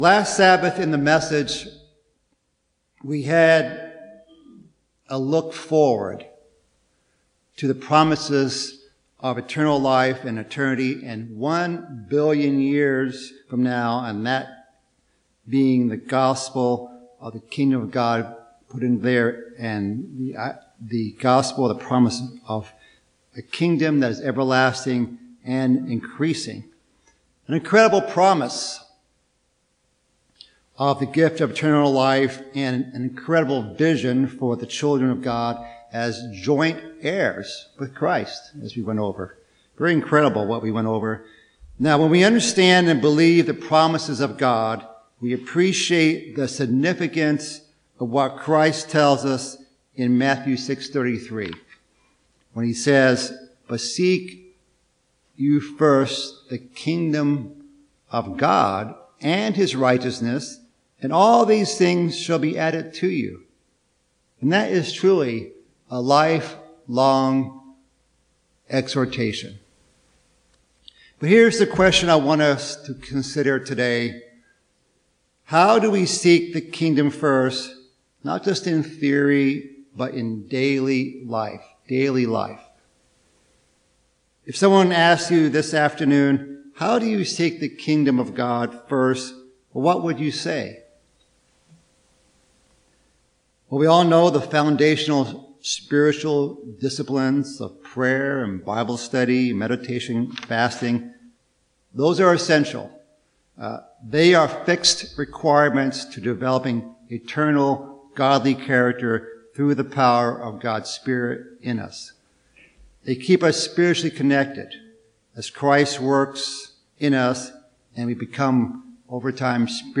Part 2 in a sermon series about our incredible future and what we can do to prepare for God's plans for us.